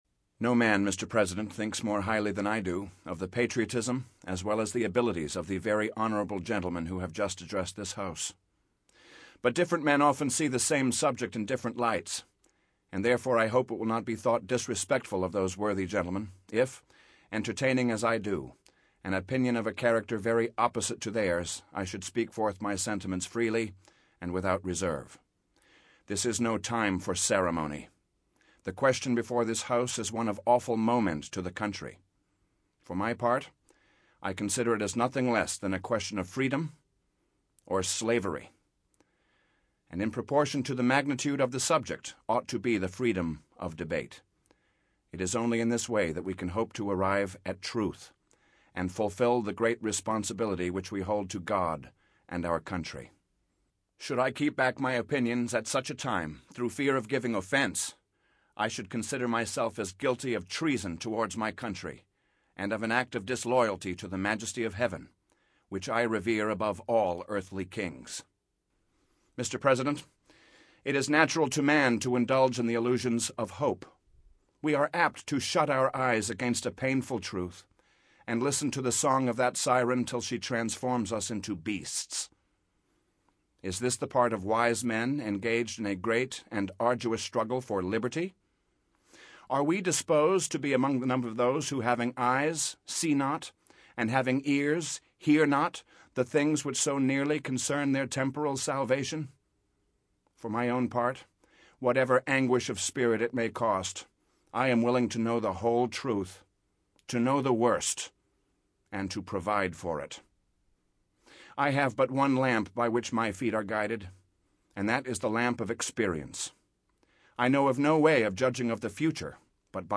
He re-creates Patrick Henry's powerful speech.
Patrick Henry - Speech - Give Me Liberty.mp3